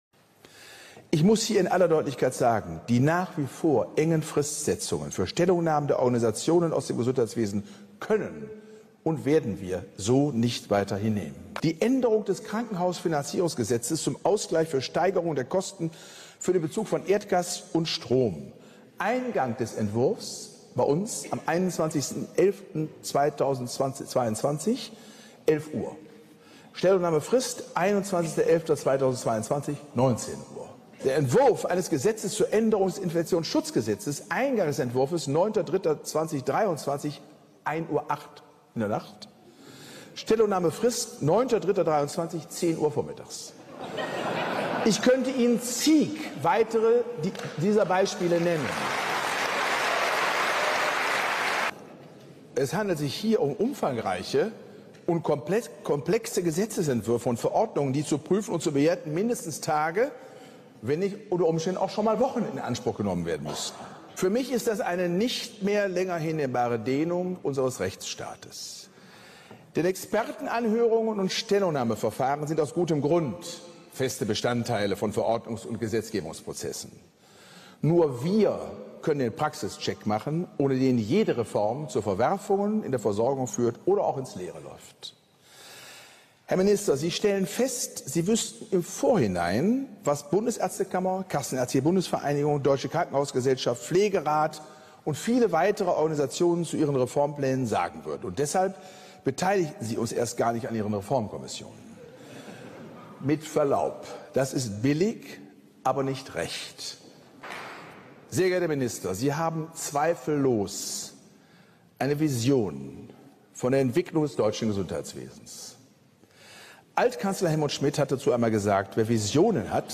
Totale fachliche ''Vernichtung'' Prof. Dr. Karl Lauterbach auf dem 127. Deutschen Ärztetag